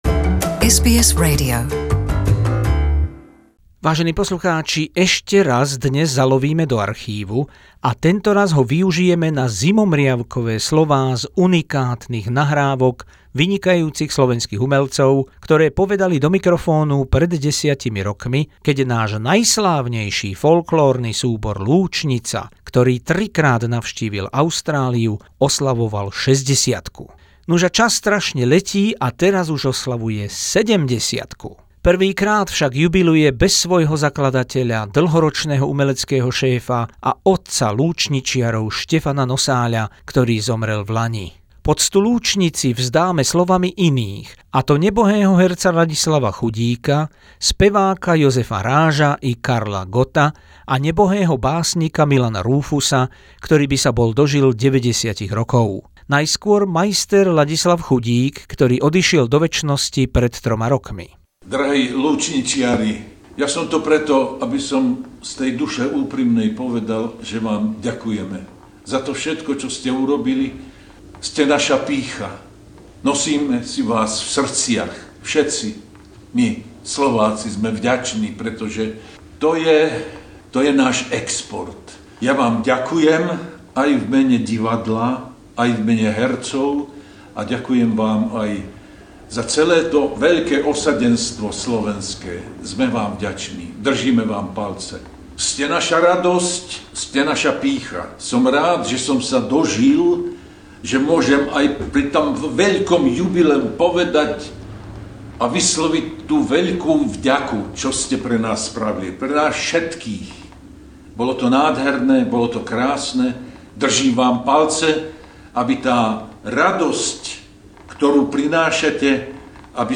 Príspevok k 70. narodeninám folklórneho súboru Lúčnica s hlasmi nesmrteľných súčasných i nebohých umelcov spred 10 rokov.